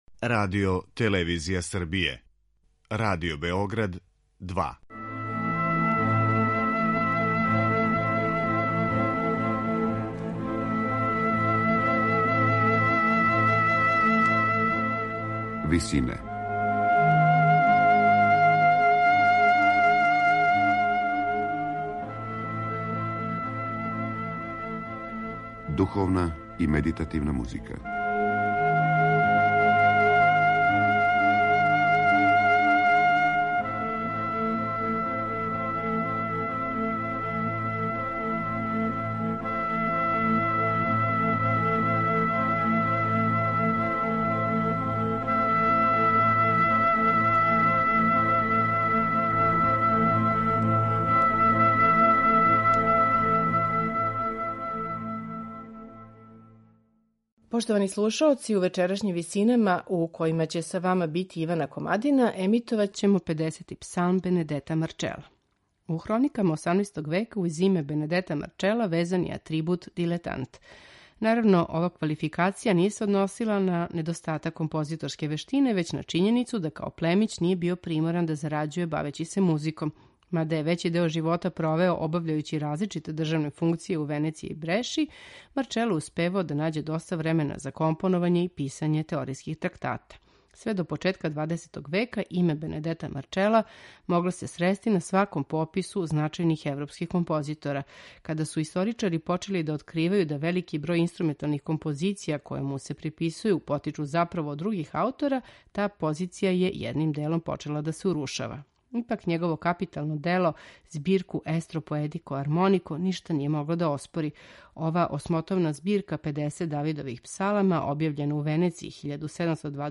алт
тенор
бас
виоле
виолончело
теорба
оргуље